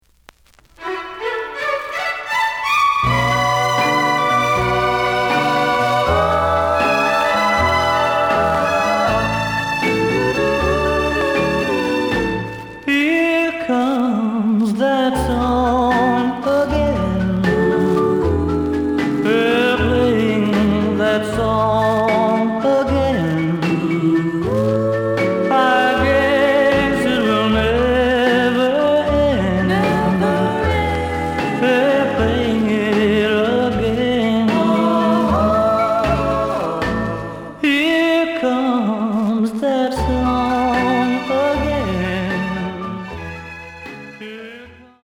The listen sample is recorded from the actual item.
●Genre: Rock / Pop